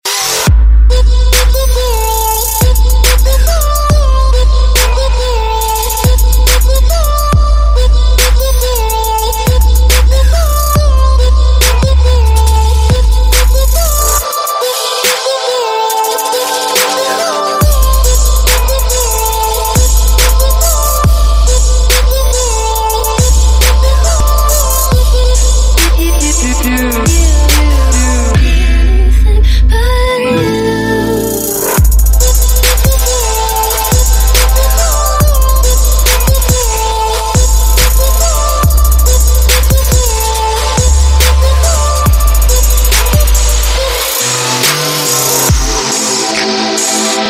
• Качество: 128, Stereo
восточные мотивы
грустные
Trap